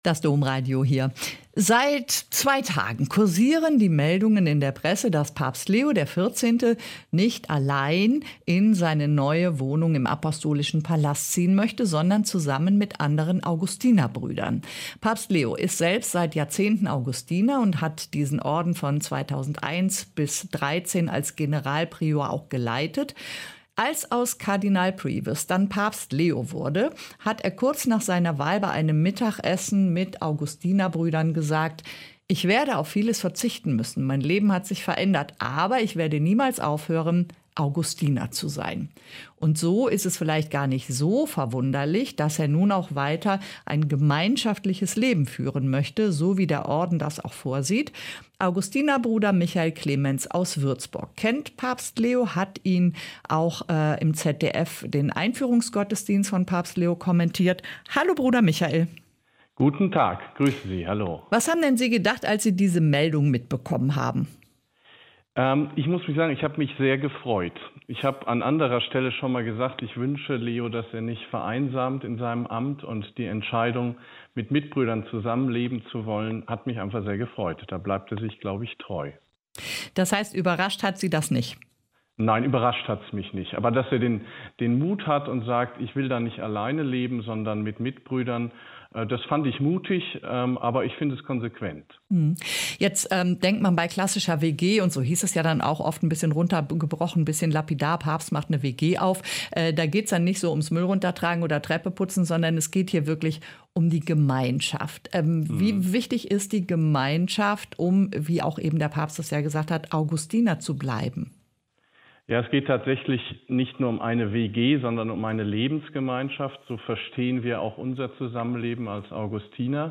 Augustinerbruder erklärt die Vorteile des Zusammenlebens im Orden